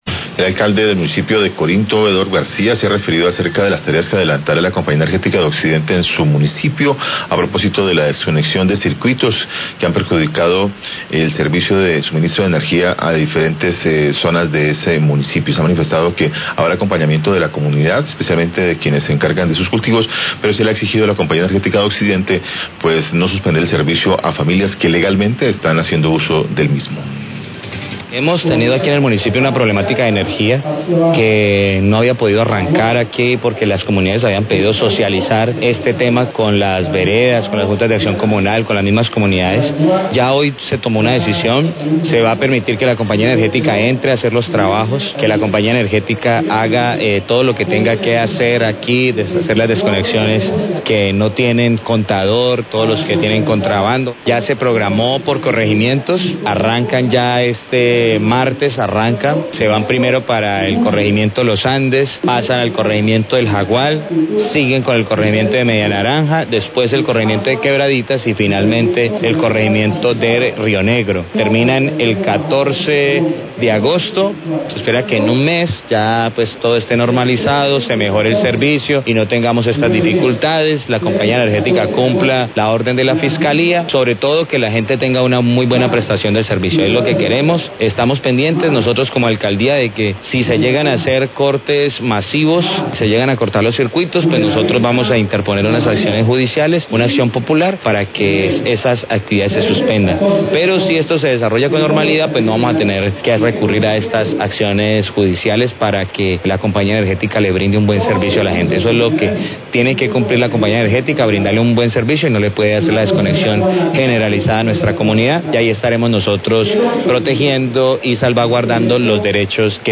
ALCALDE DE CORINTO SE REFIERE A LABORES ADELANTA LA COMPAÑÍA ENERGÉTICA
Radio